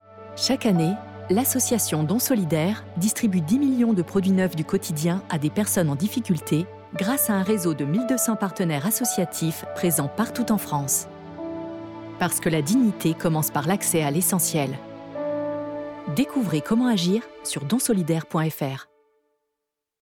Spot radio Dons solidaires Spot radio voix bienveillante voix douce voix impliquée Spot radio Catégories / Types de Voix Extrait n°1 : Télécharger MP3 Extrait n°2 : Télécharger MP3 Spot radio Catégories / Types de Voix